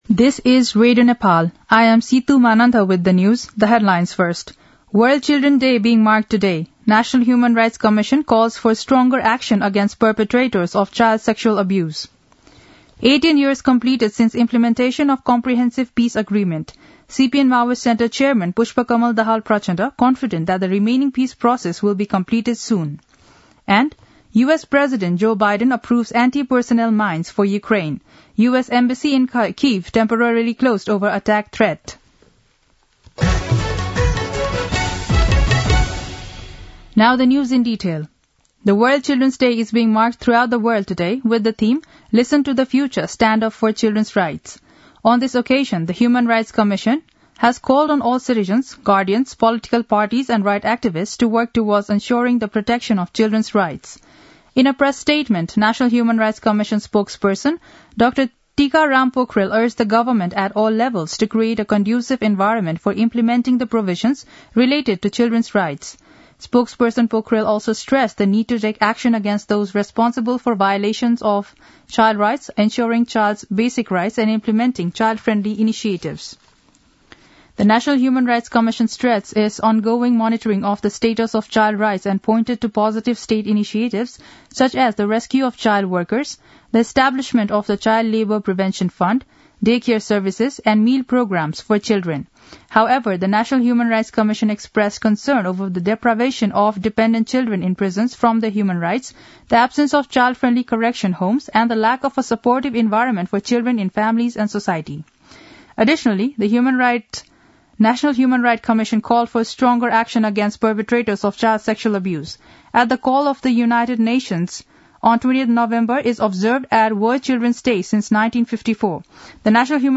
दिउँसो २ बजेको अङ्ग्रेजी समाचार : ६ मंसिर , २०८१
2-pm-english-news-1-7.mp3